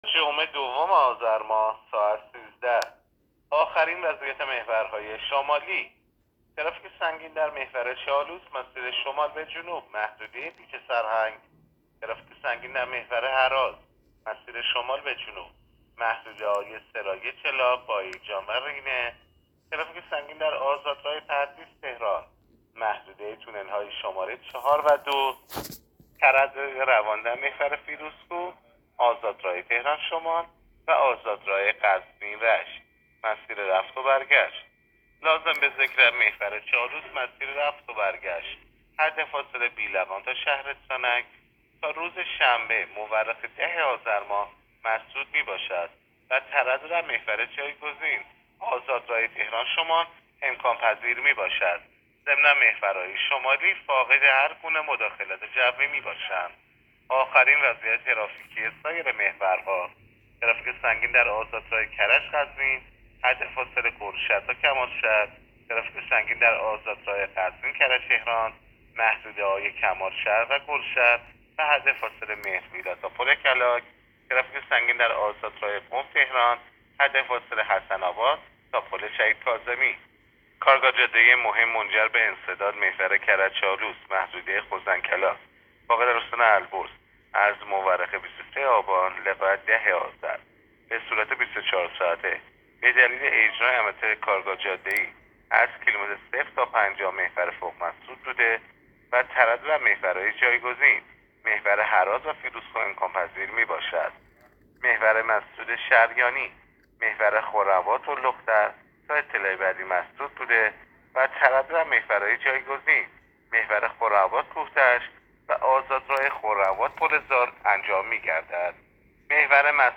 گزارش آخرین وضعیت ترافیکی جاده‌های کشور را از رادیو اینترنتی پایگاه خبری وزارت راه و شهرسازی بشنوید.
گزارش رادیو اینترنتی از آخرین وضعیت ترافیکی جاده‌ها تا ساعت ۱۳ دوم آذر؛